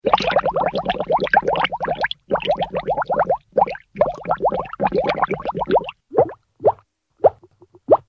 sfx bubbles